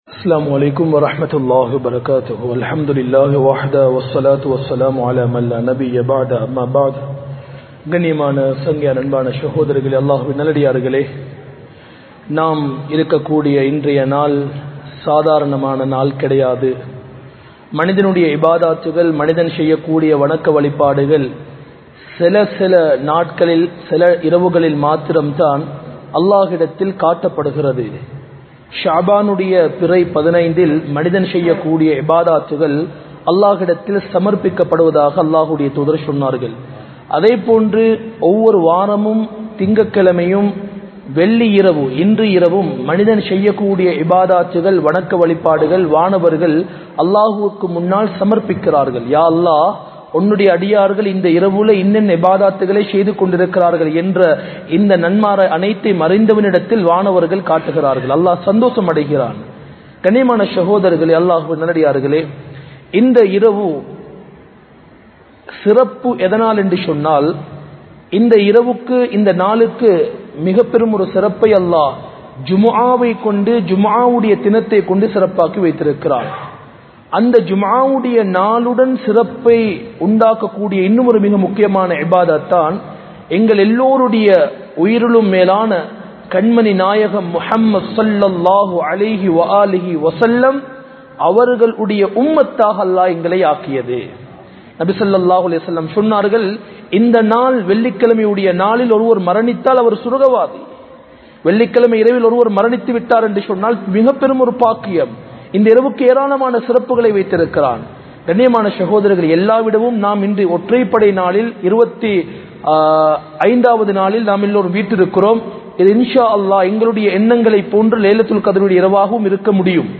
Bayans
Colombo 04, Majma Ul Khairah Jumua Masjith (Nimal Road)